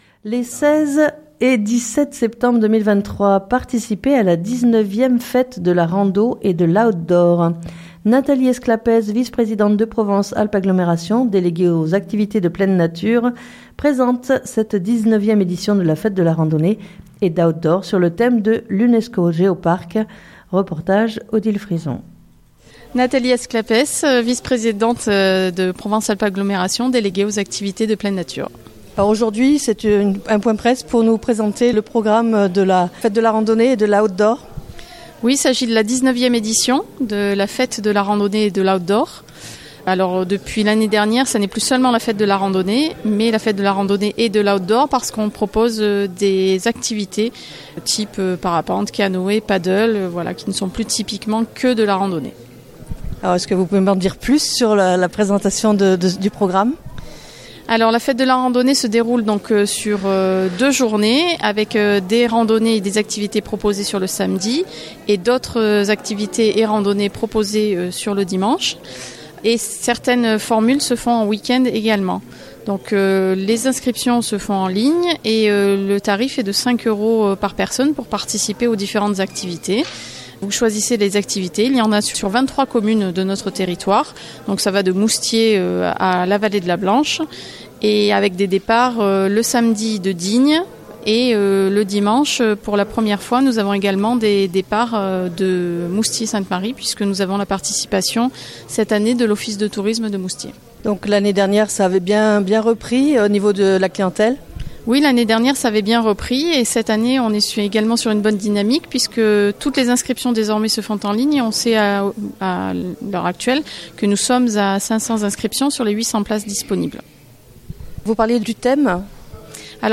Nathalie Esclapez Vice-Présidente de Provence Alpes Agglomération Déléguée aux activités de Pleine Nature présente la 19ème édition de la Fête de la randonnée et d'Outdoor sur le Thème de l'UNESCO GEO PARC. Reportage